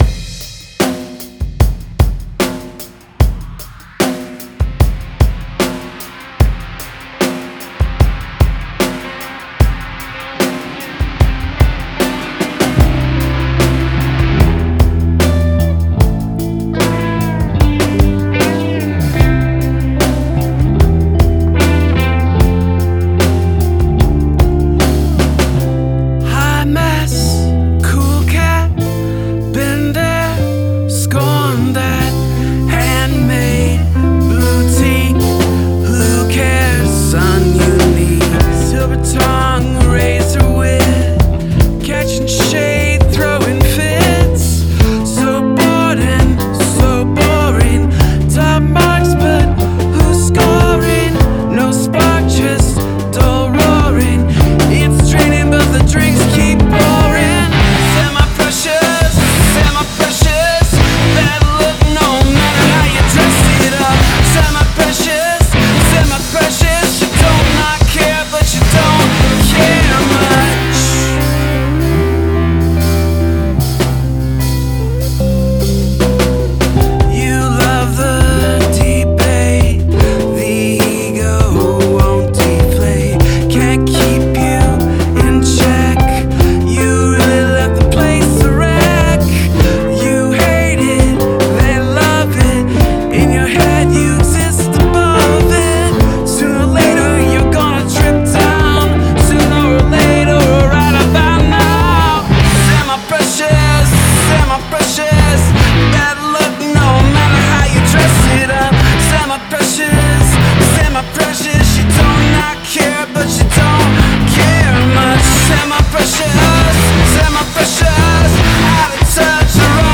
Gradual emphasis of repetitions
Very moody vibe right from the start.